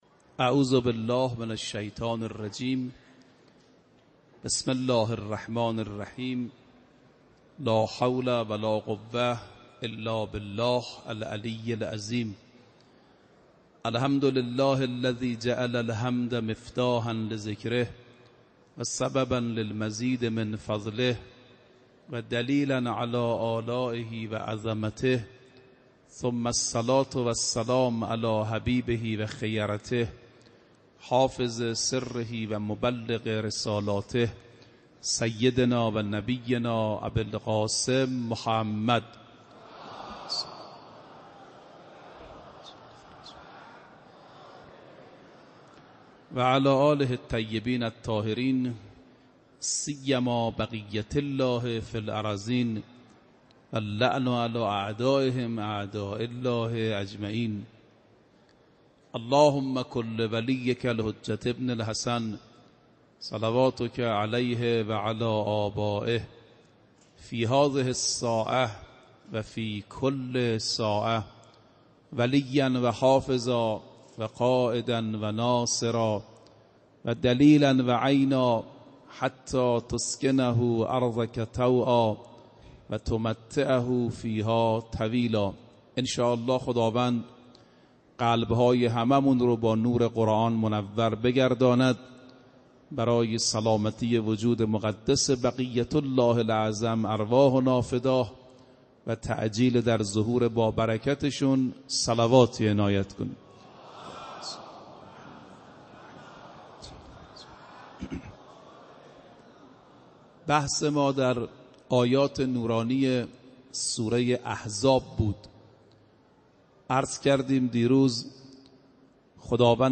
روز پنجم رمضان 96 - حرم حضرت معصومه - سخنرانی